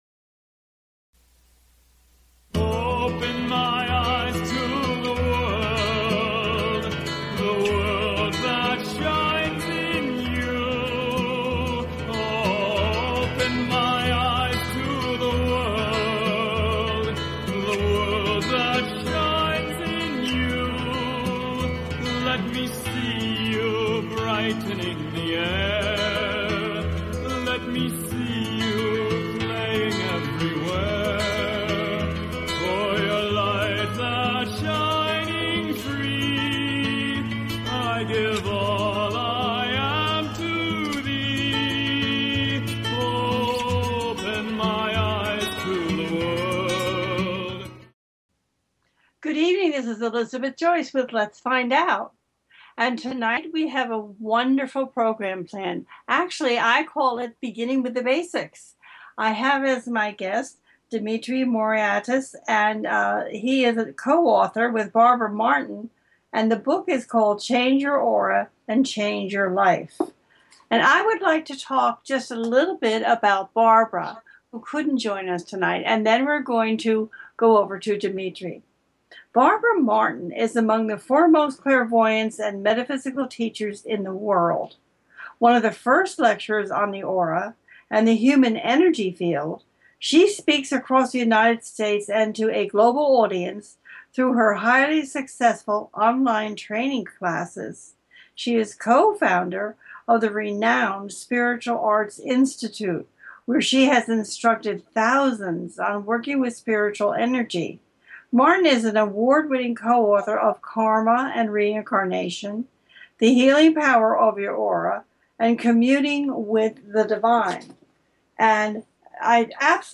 Let’s Find Out brings to its listeners illuminating and enthralling exploration of the connection between our minds and our bodies. This show brings a series of fascinating interviews with experts in the field of metaphysics.
The listener can call in to ask a question on the air.